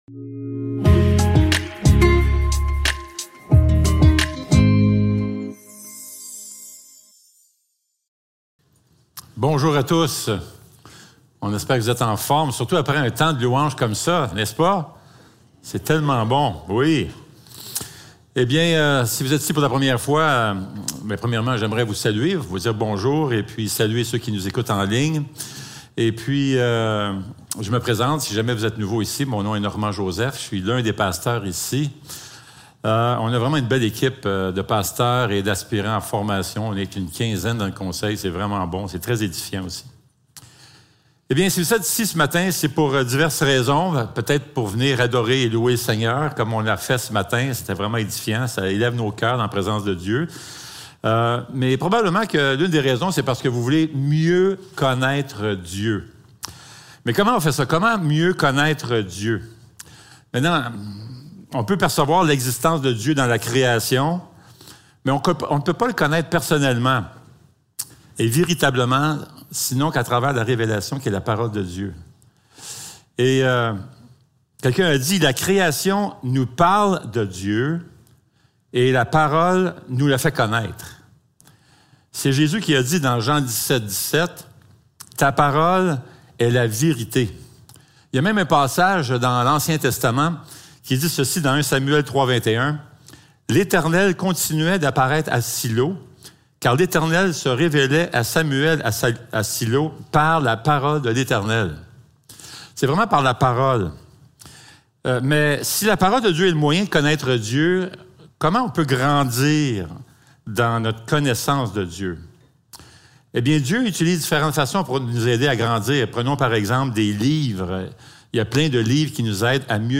1 Corinthiens 1.1-7 Service Type: Célébration dimanche matin Description